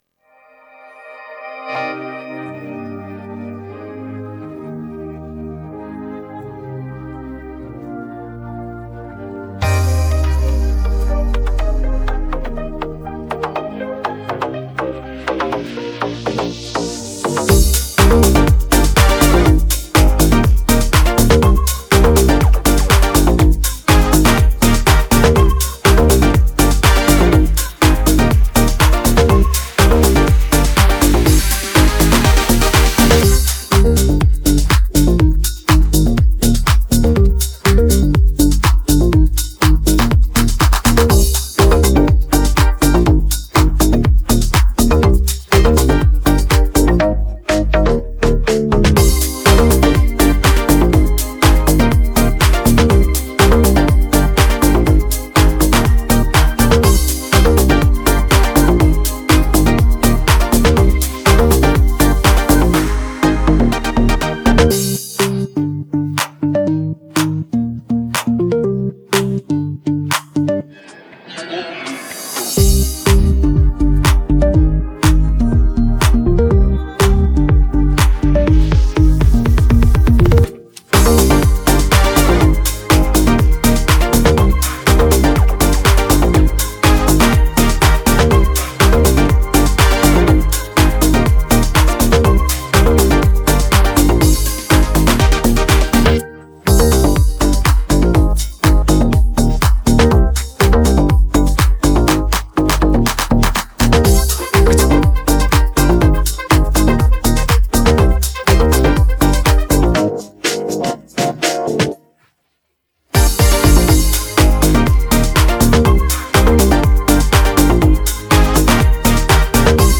Ritm__instmental минусовка
Ritm__instmental-minusovka.mp3